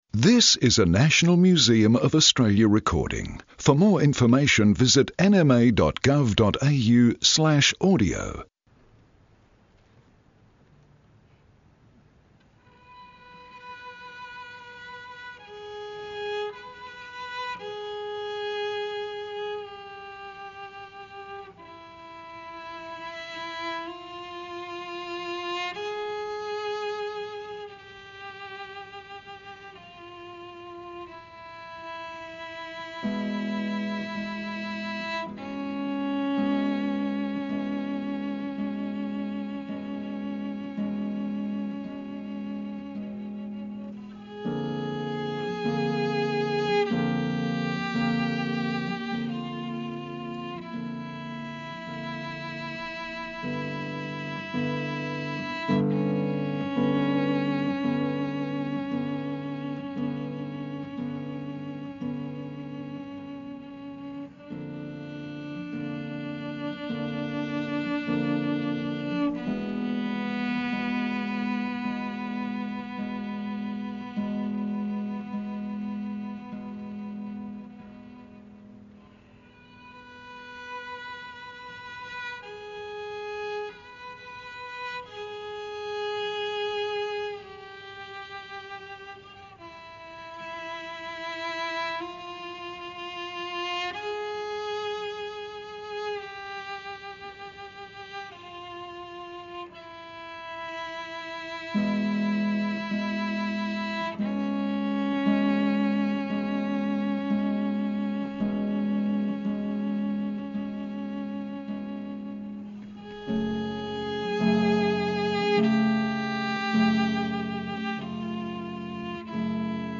the AE Smith cello